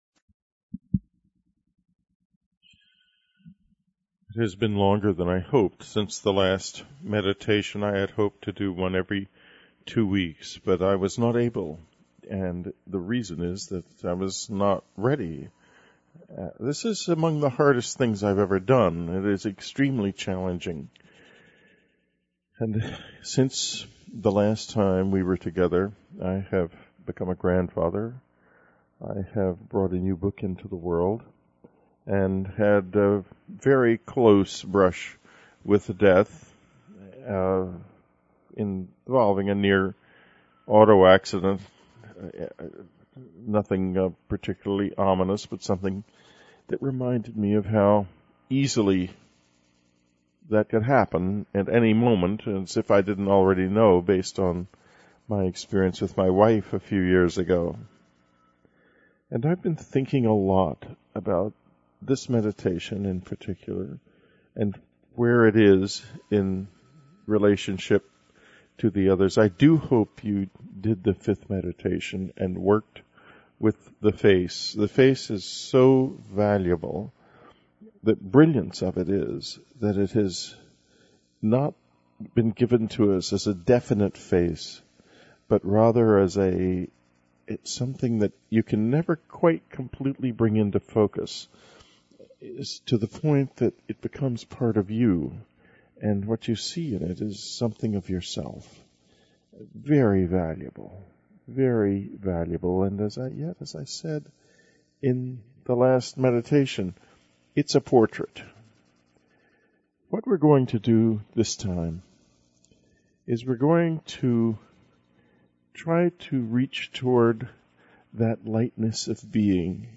This week we return to Whitley Strieber’s astonishing Crop Circle Meditation Series, that uses crop circles in a whole new way. He delivers his sixth of nine meditations, and it is a wonderful companion to the interview with Wayne Dyer, because this meditation series is also about changing our thought patterns–in this case by using the very Yin-Yang symbol that is also at the heart of the Tao.